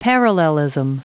Prononciation du mot parallelism en anglais (fichier audio)
Prononciation du mot : parallelism